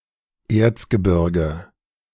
The Ore Mountains (German: Erzgebirge, pronounced [ˈeːɐtsɡəˌbɪʁɡə]